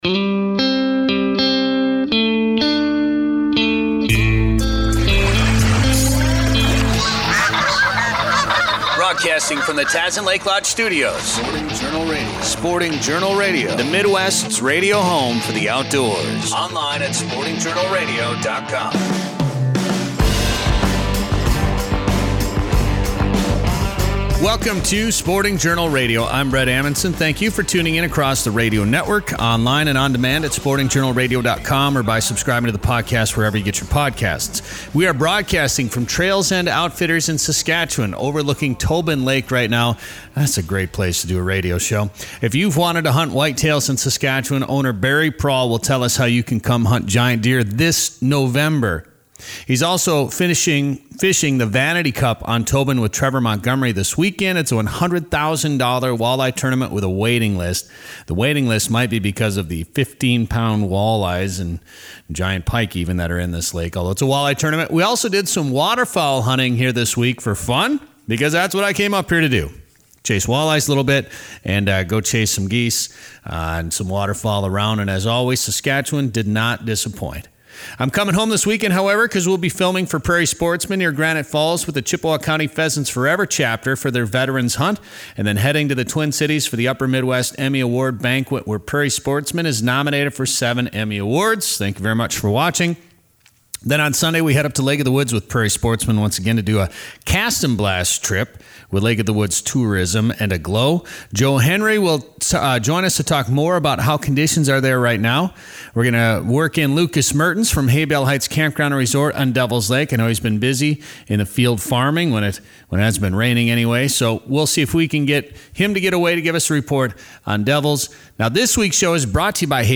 Search for “Sporting Journal Radio” Snow falls during a recent hunt in Saskatchwan This week on Sporting Journal Radio, we’re broadcasting from Tobin Lake at Trails End Outfitters.